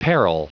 Prononciation du mot peril en anglais (fichier audio)
Prononciation du mot : peril